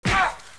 GRUNT6.WAV